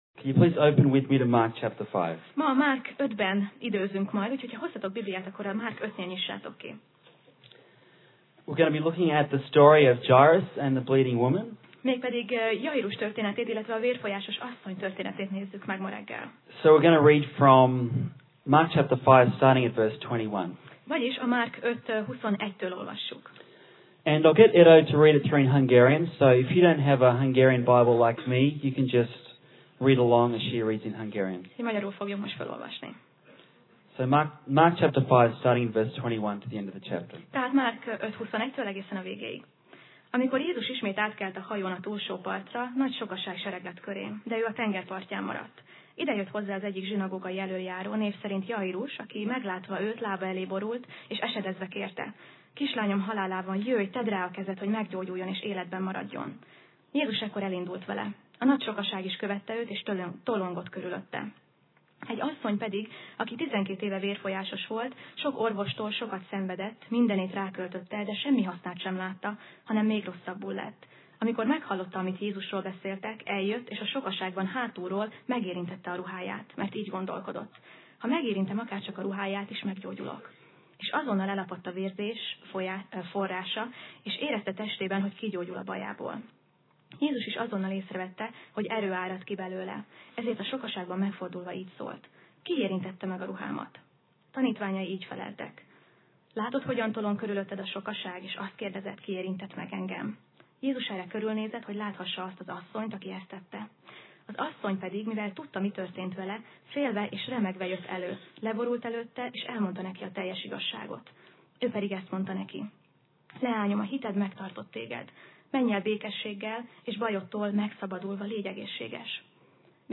Sorozat: Tematikus tanítás Passage: Márk (Mark) 5:21-43 Alkalom: Vasárnap Reggel